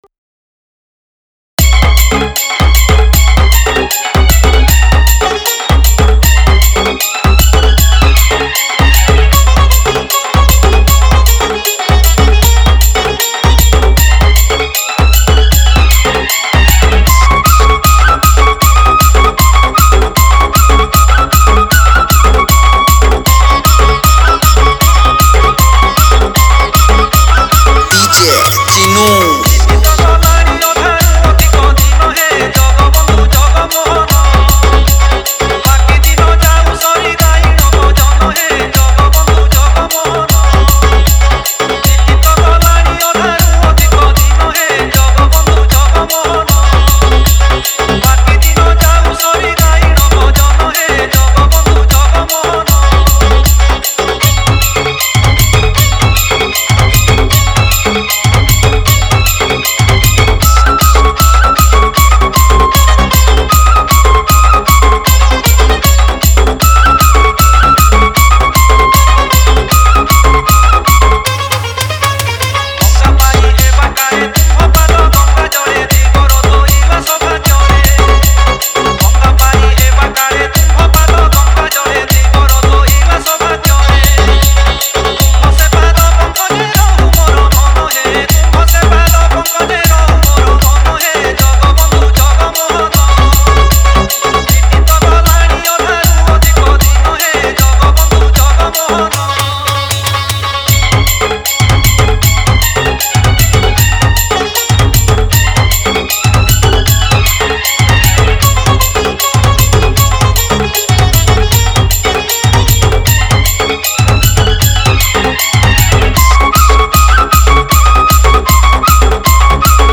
Category:  Odia Bhajan Dj 2021